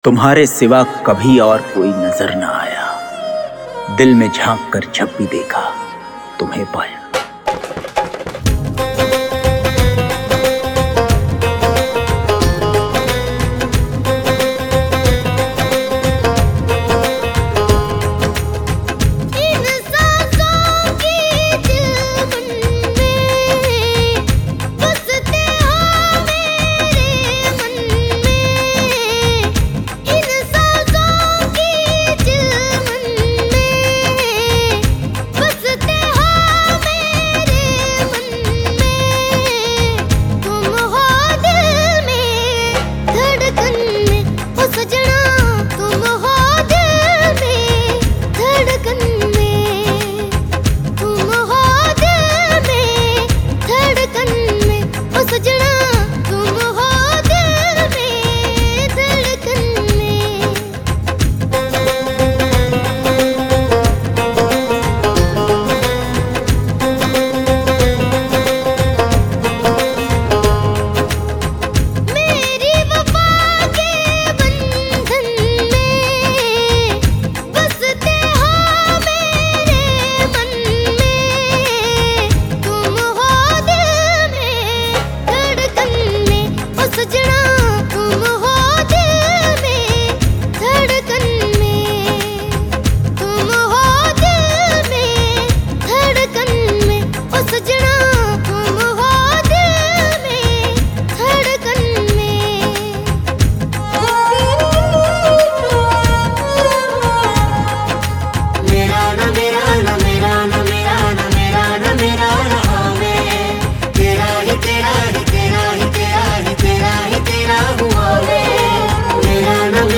Indian POP Mp3 Song